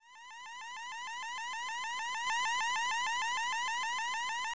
hacking.wav